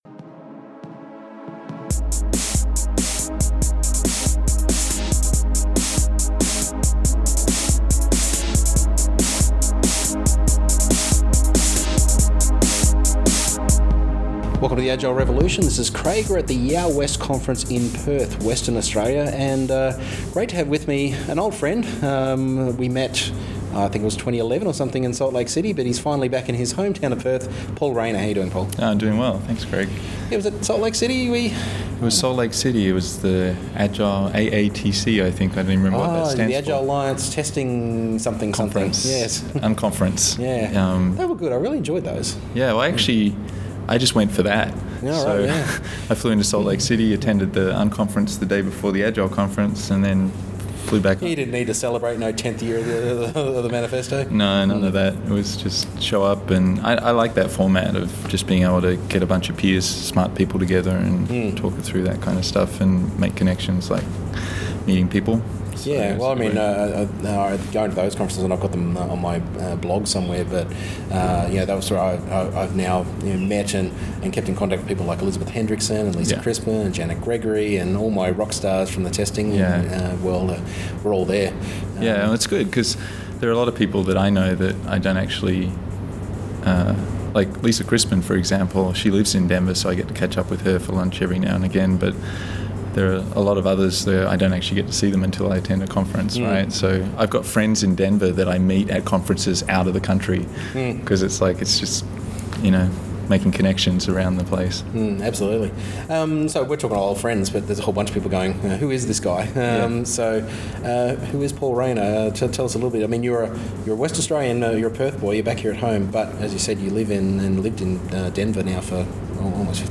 at YOW! West in Perth